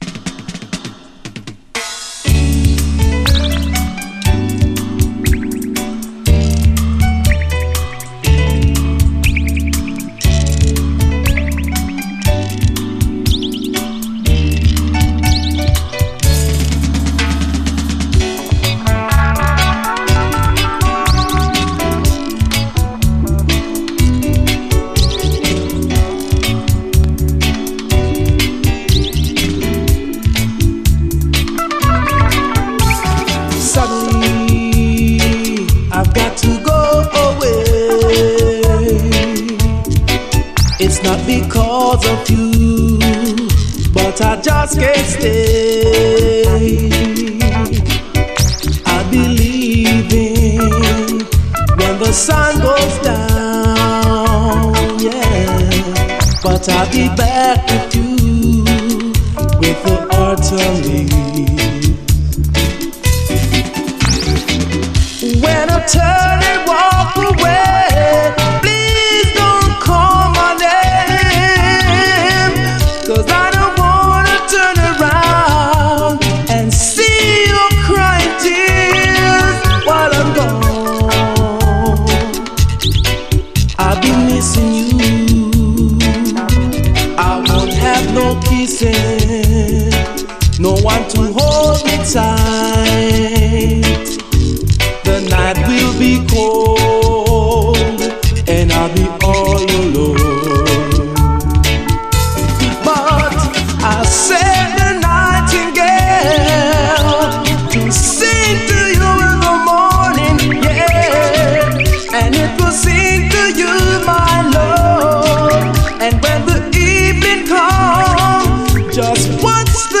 REGGAE
激リコメンド！エレガンスと哀愁が同居するキラー・ソウルフルUKルーツ！
イントロのエレガントなピアノ・フレーズ、そこに続く荒ぶるギター・プレイを聴いただけで痺れます。